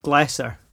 [glEHsser]